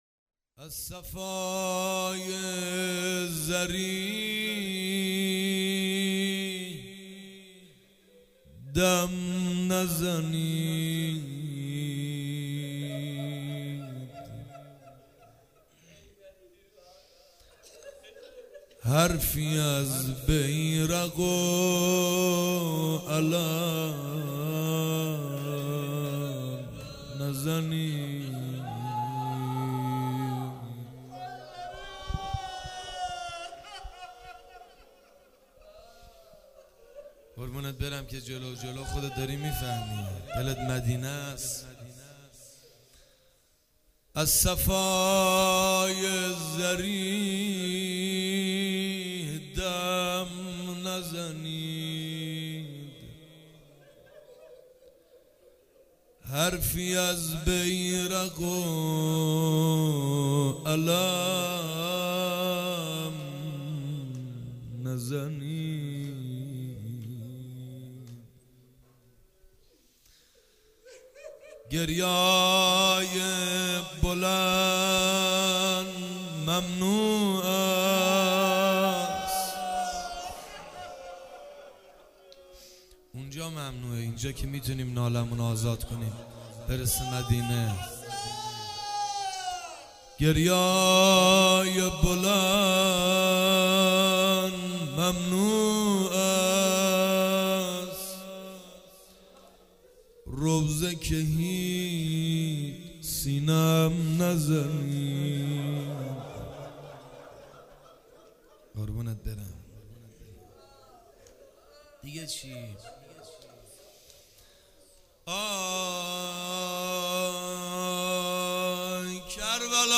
تکیه | روضه امام حسین ع